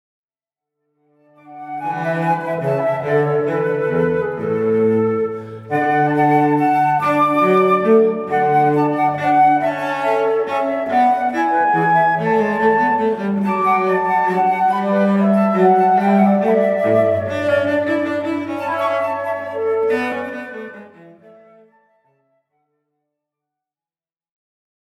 en ré majeur-Corrente-Allegro